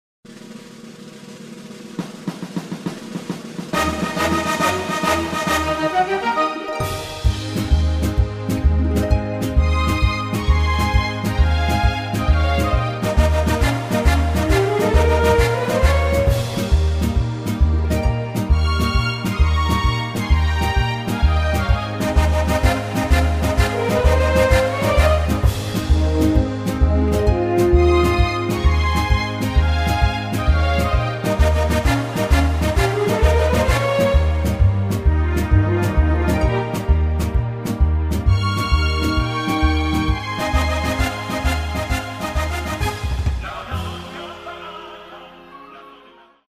avec choeurs